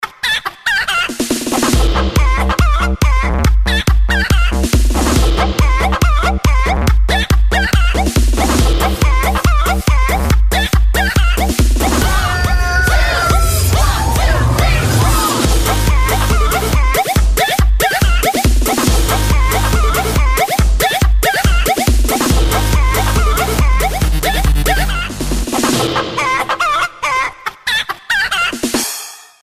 • Качество: 320, Stereo
громкие
прикольные
смешные